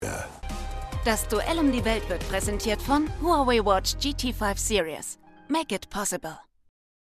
TVC: Huawei Watch GT5 Series (Sponsorig “Das Duell um die Welt” / Pro7)